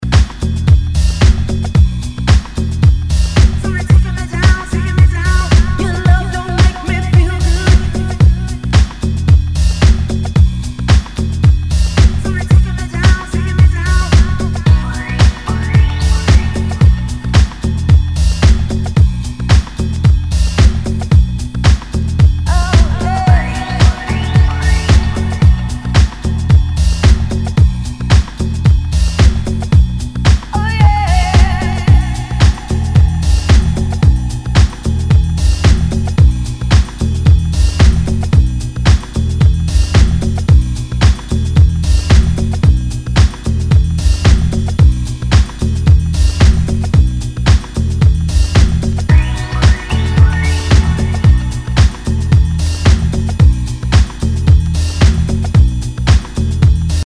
HOUSE/TECHNO/ELECTRO
ナイス！ディープ・ハウス！
少し盤に歪みあり。